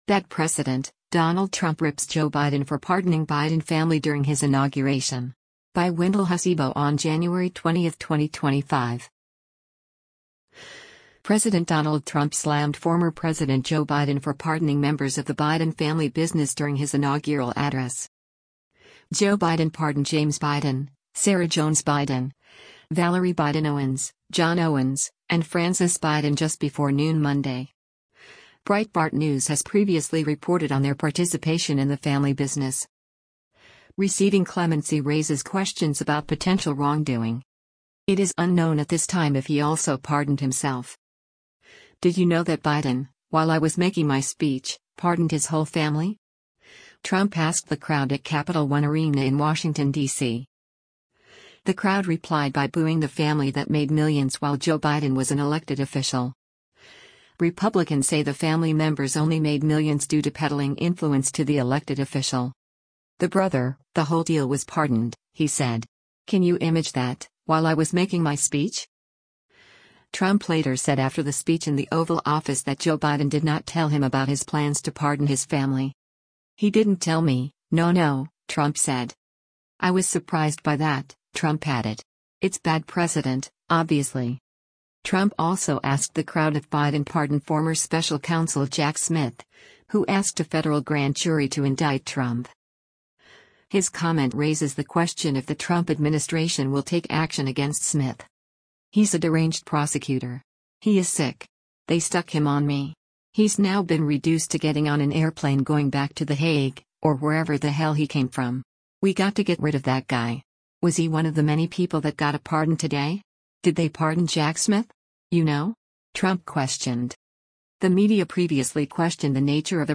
“Did you know that Biden, while I was making my speech, pardoned his whole family?” Trump asked the crowd at Capitol One Arena in Washington, DC.
The crowd replied by booing the family that made millions while Joe Biden was an elected official.